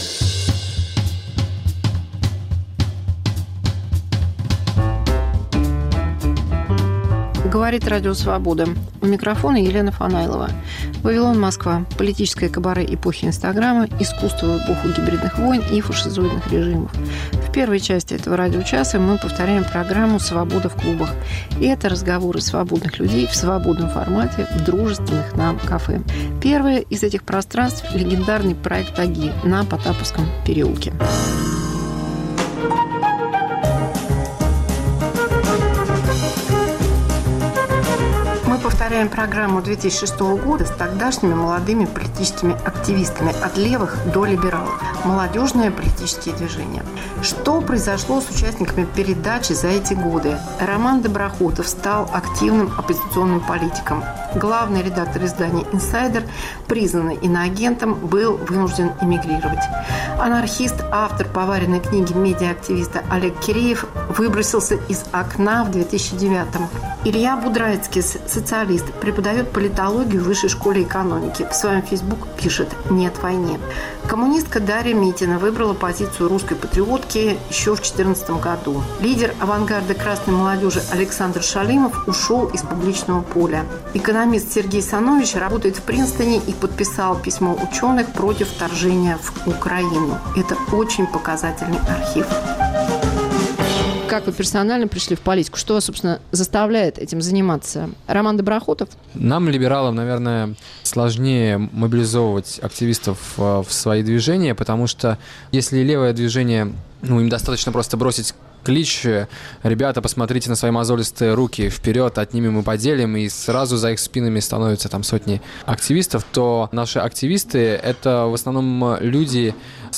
Антивоенный вечер в Мемориале, часть 3